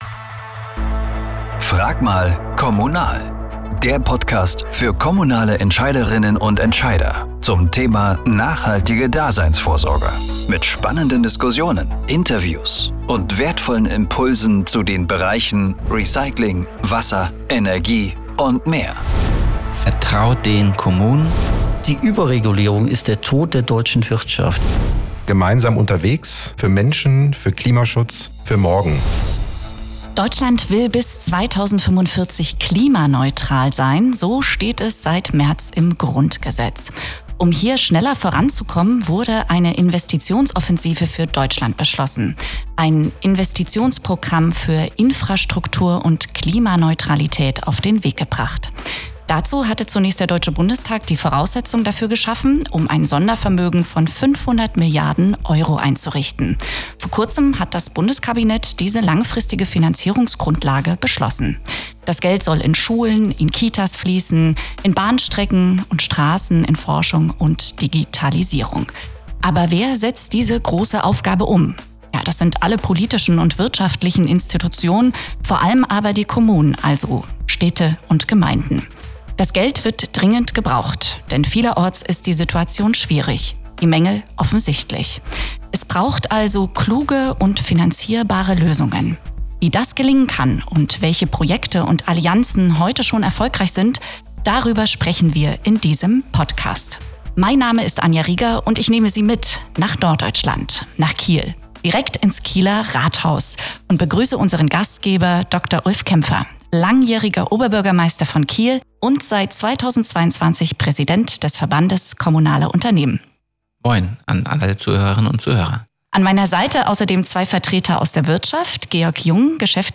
Eine Diskussion aus dem Rathaus in Kiel